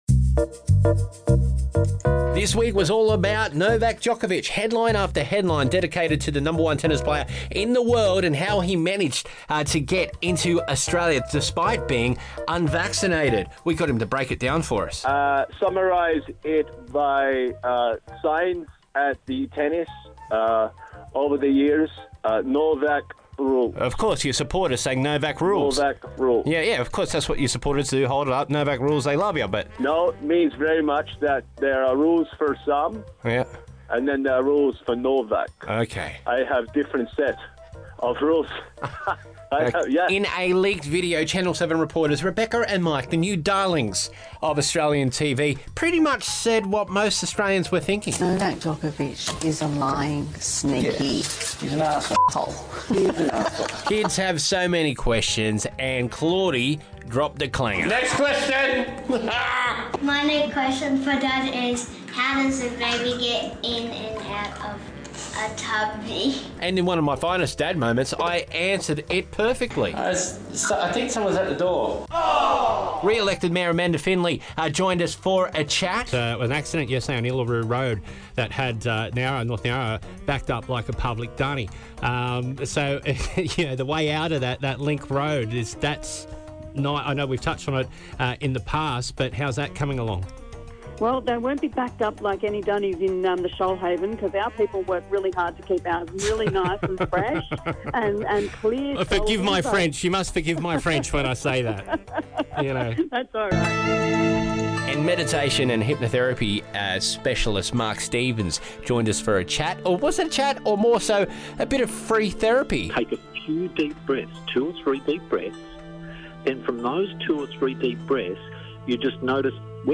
Check out some of the fun from this weeks breakfast show